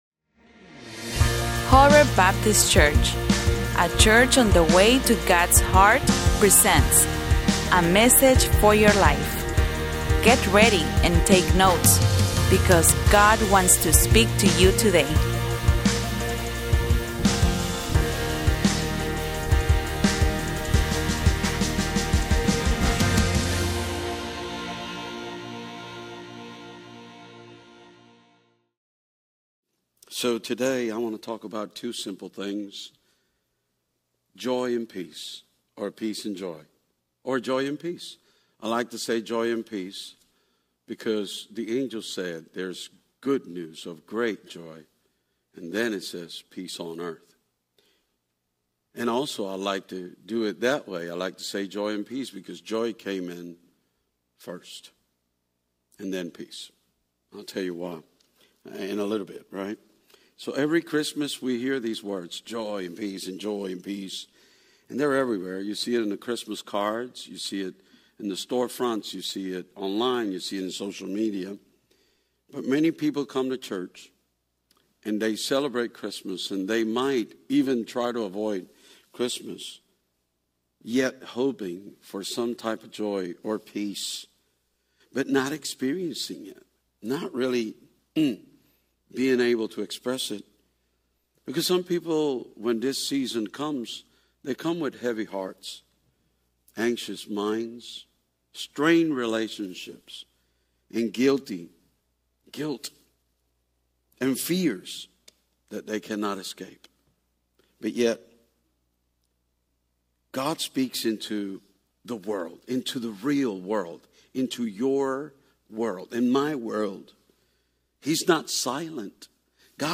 Sermons Archive - HOREBNOLA